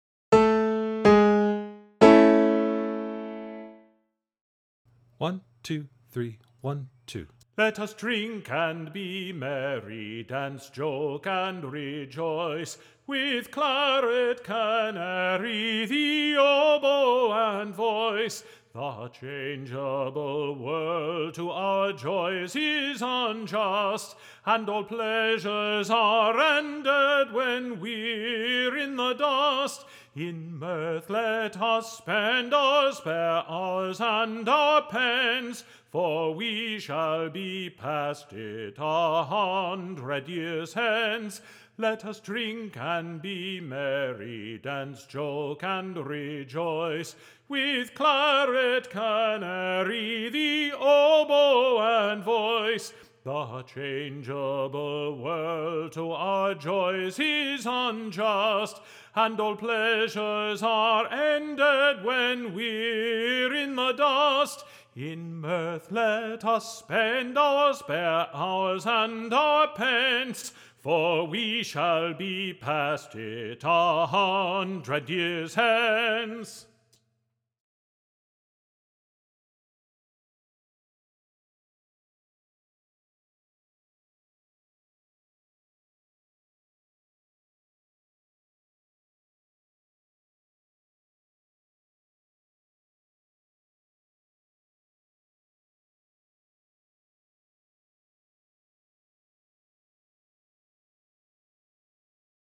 Let-Us-Drink_one-voice.mp3